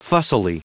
Prononciation du mot fussily en anglais (fichier audio)
Prononciation du mot : fussily